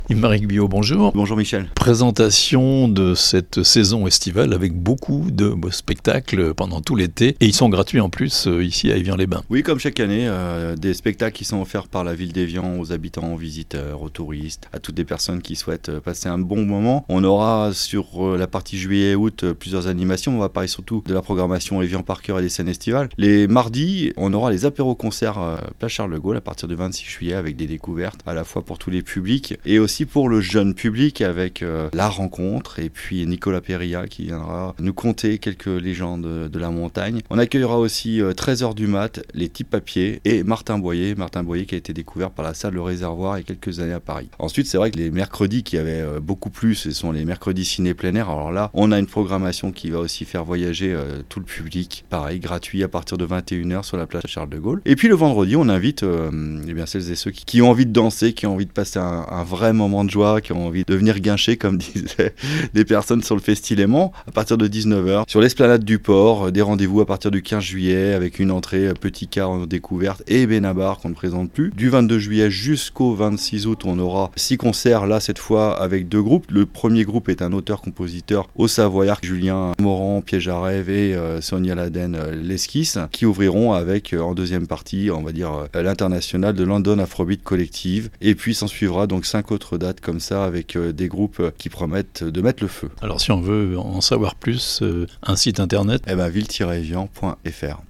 Après FestiLéman et ses 3 jours de concerts, d'autres spectacles gratuits tout l'été à Evian (interview)